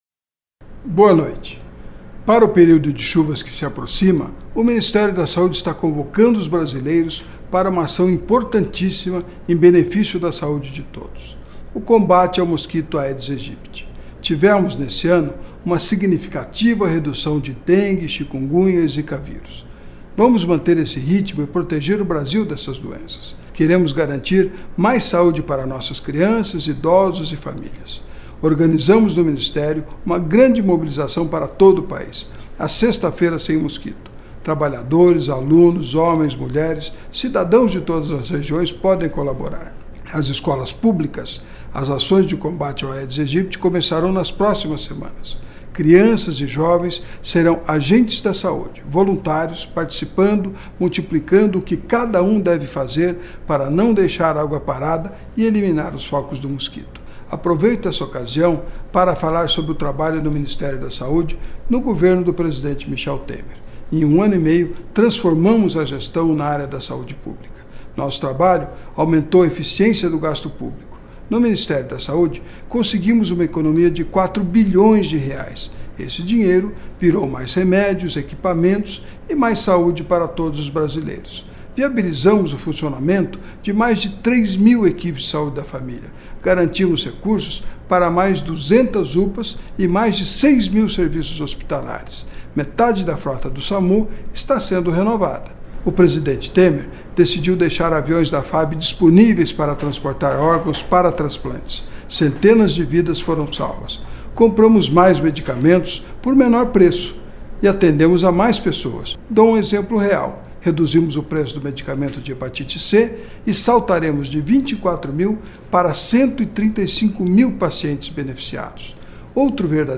Pronunciamento do Ministro da Saúde Ricardo Barros